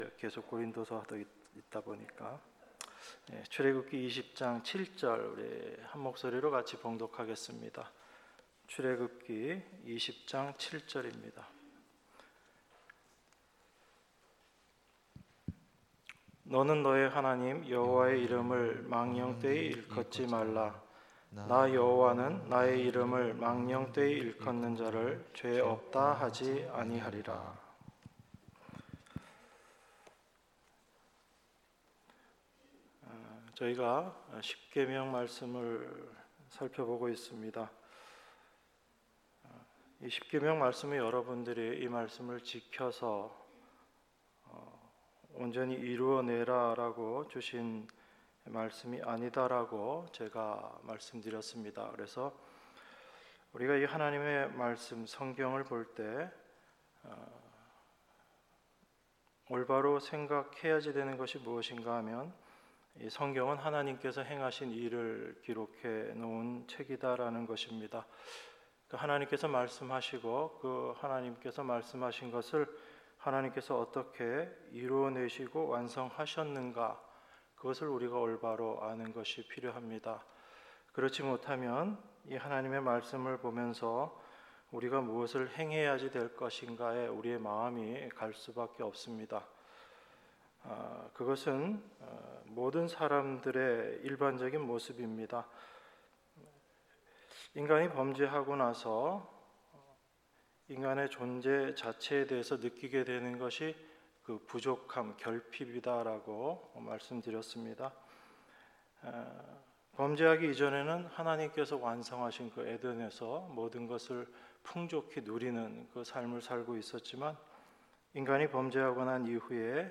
수요예배 출애굽기 20장 7절